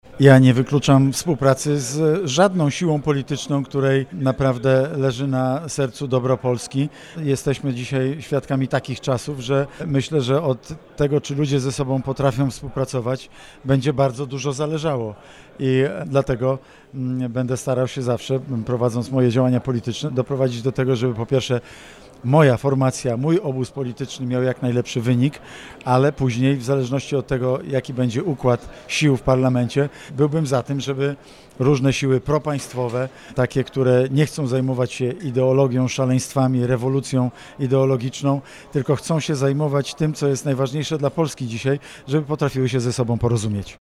-Nie wykluczam sojuszu z żadną partią, której zależy na dobru Polski, mówi Mateusz Morawiecki, były premier RP.